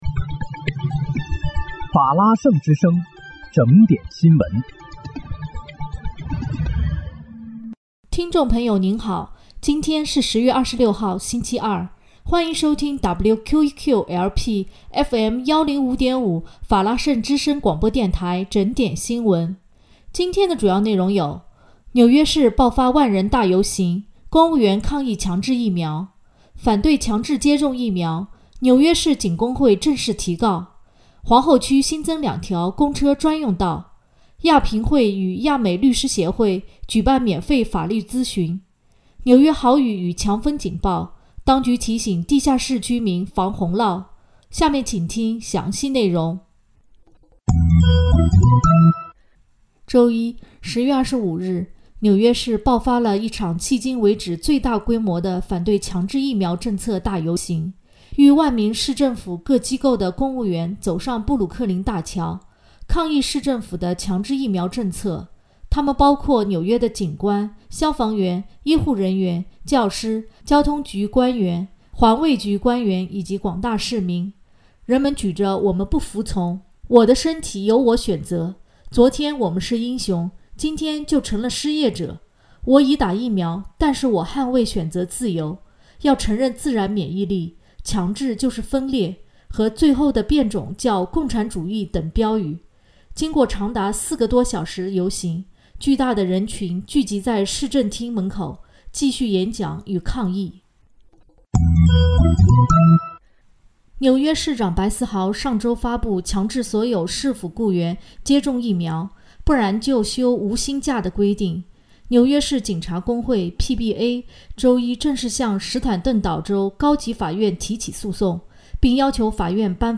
10月26日（星期二）纽约整点新闻